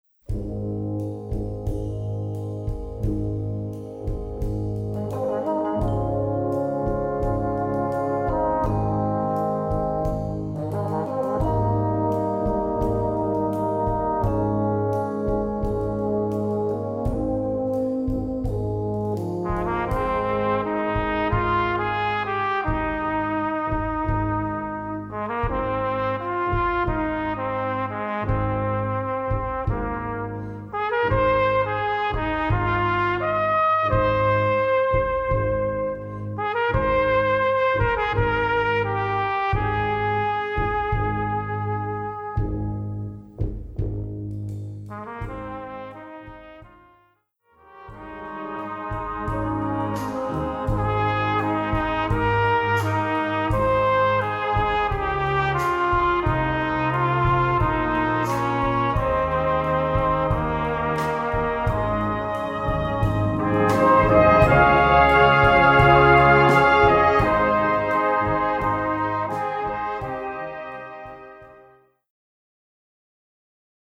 Gattung: Solo für diverse Instumente und Blasorchester
Besetzung: Blasorchester
Solo B-Instument und Blasorchester.